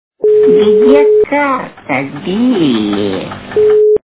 При прослушивании Где карта, - Билли? качество понижено и присутствуют гудки.